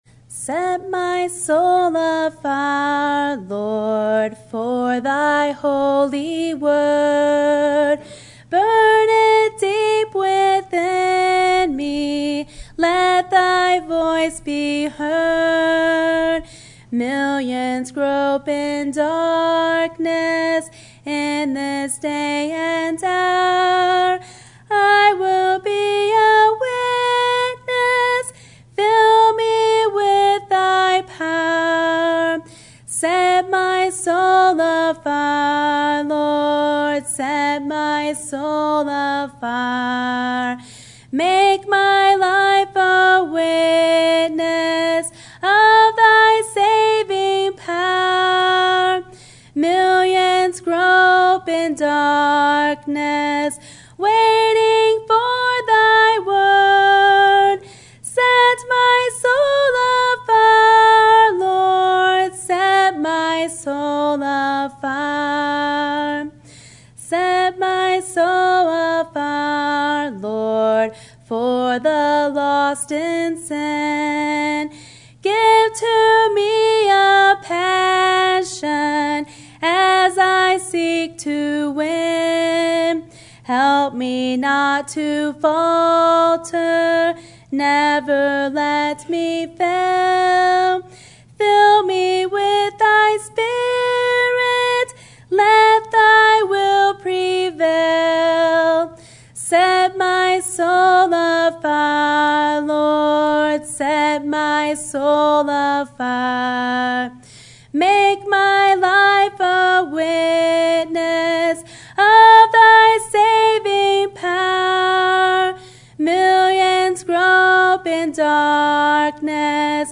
Sermon Topic: Philippians, The Pursuit of Joy Sermon Type: Series Sermon Audio: Sermon download: Download (26.15 MB) Sermon Tags: Philippians Joy Life Death